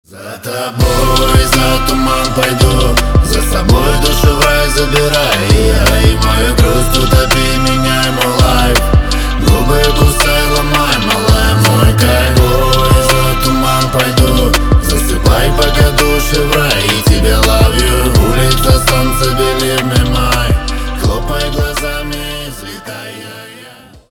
рэп , хип-хоп